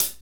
HAT R B CH0D.wav